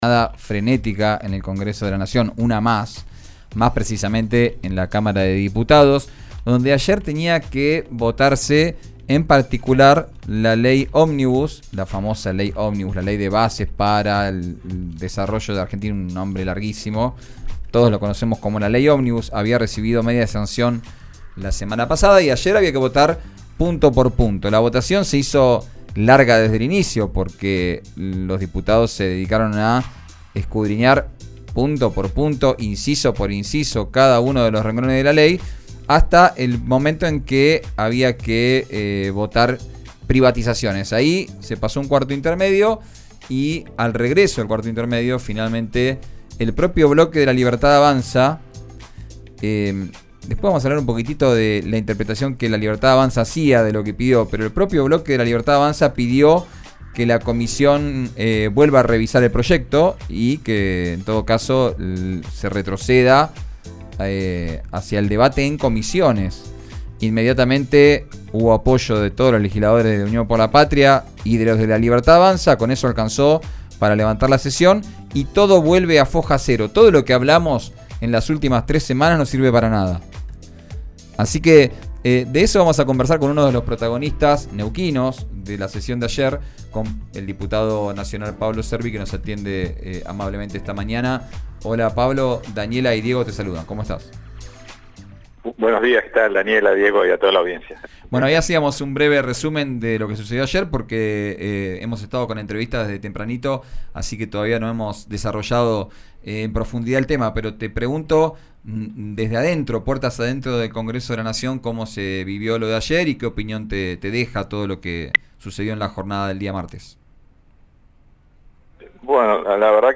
El diputado nacional por Neuquén e integrante del bloque de la Unión Cívica Radical apuntó contra el Ejecutivo por la falta de acuerdos y el regreso a comisión del proyecto. Escuchá la entrevista completa en RÍO NEGRO RADIO.